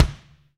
BD BD3H.wav